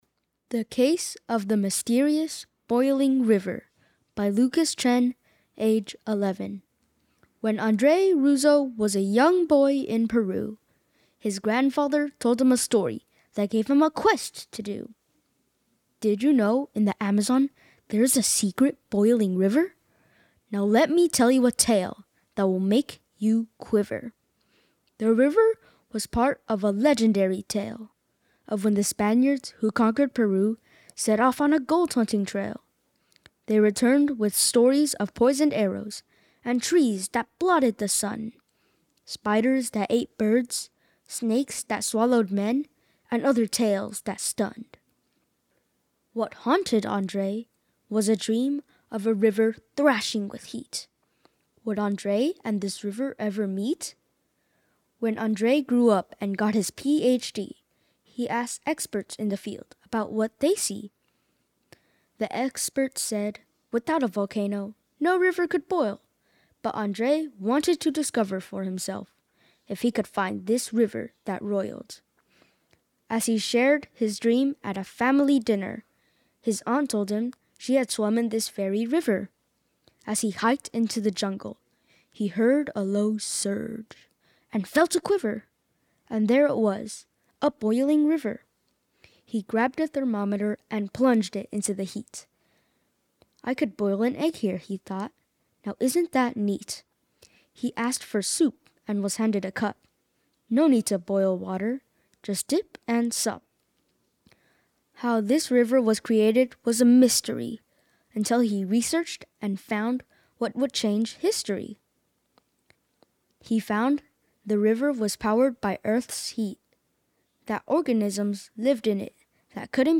Reading of the poem